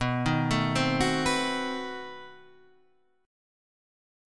B7b9 chord {x 2 4 5 4 5} chord